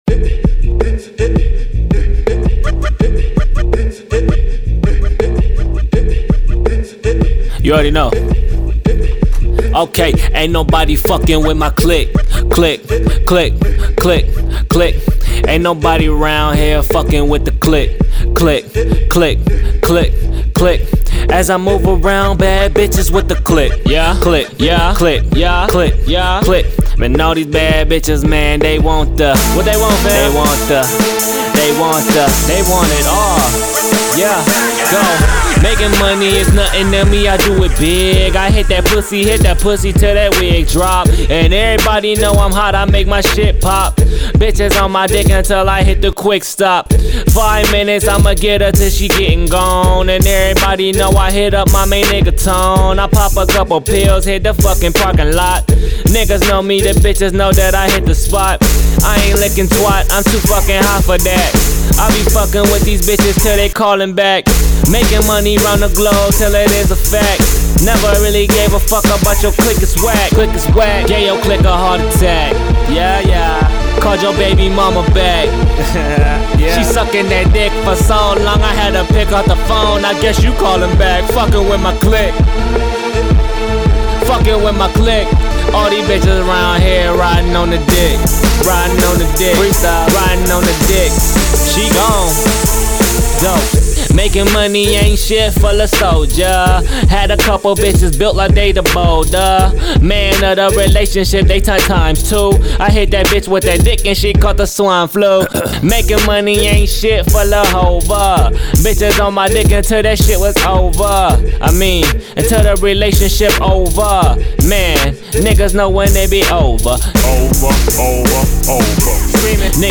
My vocals
idk i know one thing i need a better studio performance. but eq wise an mixing wise what else could help this even tho its just a freestyle i didnt cut the breaths or anything like that i coulda done all that but i just didnt. it was just a quick free. let me know thanks guyssss